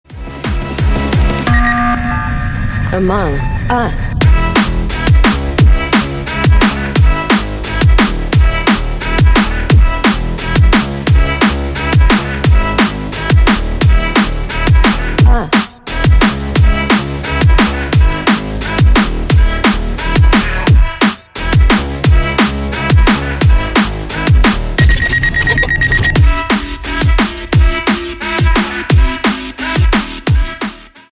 Рингтоны Ремиксы
Рингтоны Электроника